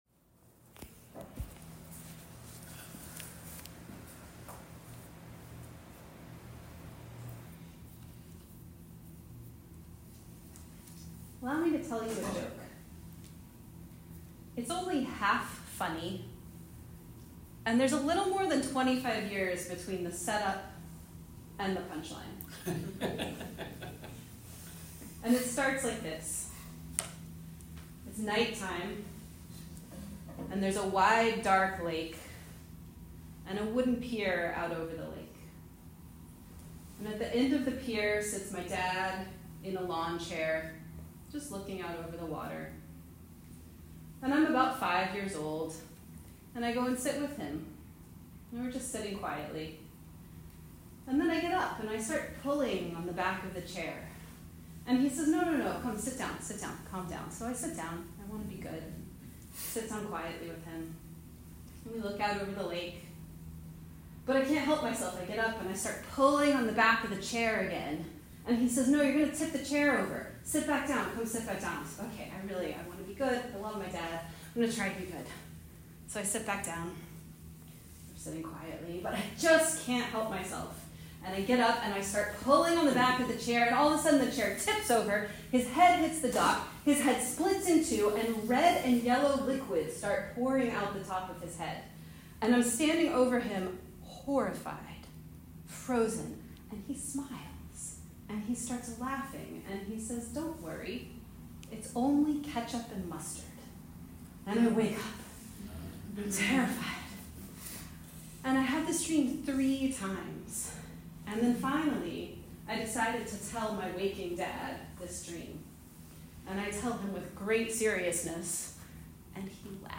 Listen to my story of following my dreams to become a Processworker I told this story live